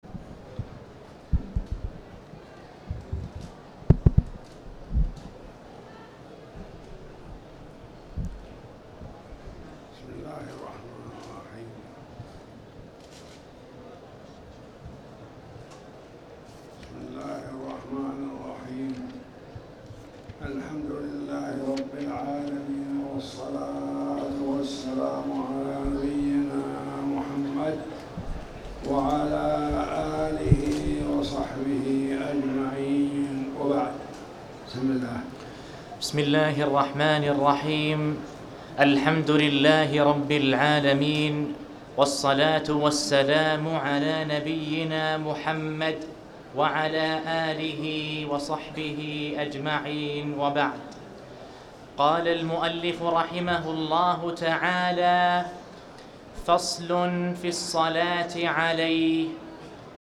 تاريخ النشر ٢١ ربيع الأول ١٤٣٩ هـ المكان: المسجد الحرام الشيخ